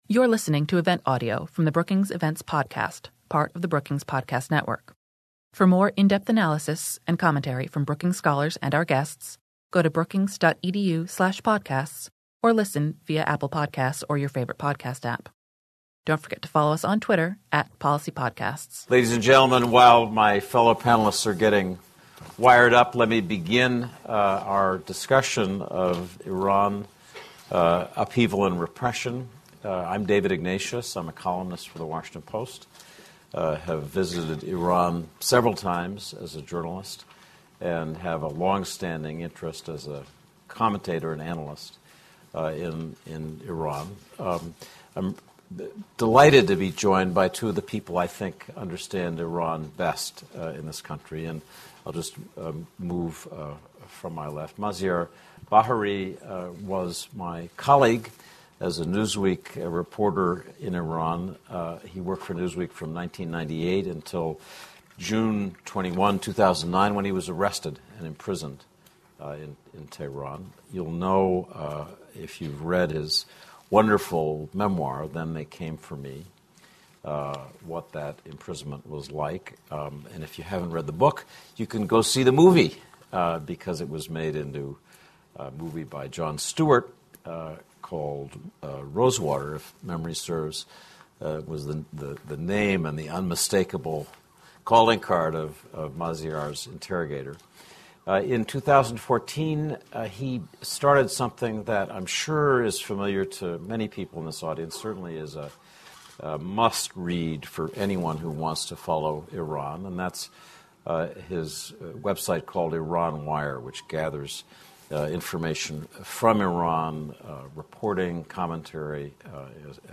Washington Post Columnist David Ignatius led a conversation featuring journalist and filmmaker Maziar Bahari, whose memoir of his own imprisonment in Iran was the basis for the 2014 film “Rosewater.”